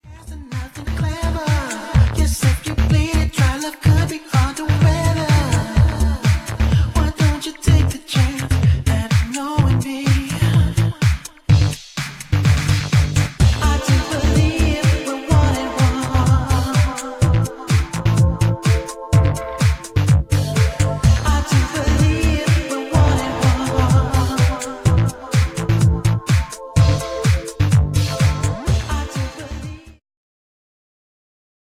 The main track has one of those infectious rising bass
Always moving this is high energy electro/house/disco, the